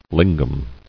[lin·gam]